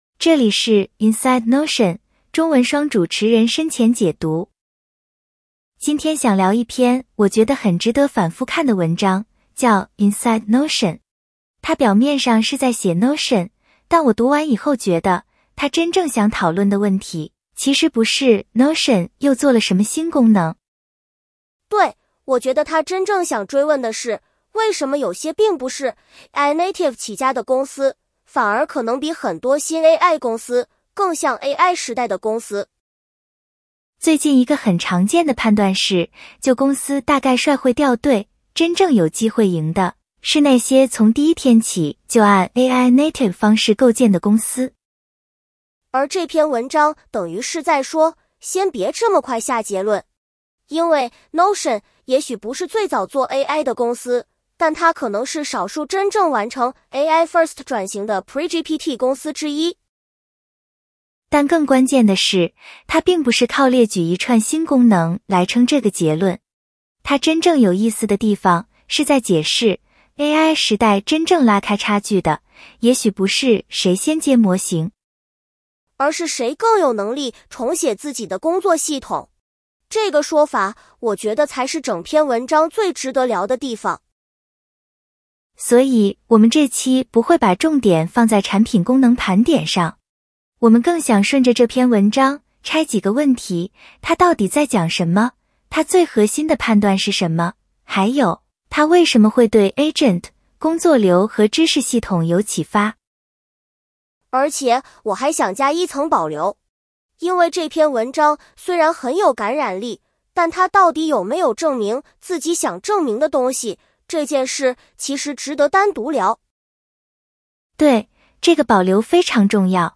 一集围绕 Colossus 长文《Inside Notion》的中文双主持人研究型节目，同时附上已获授权公开发布的完整中文译文，便于听读结合。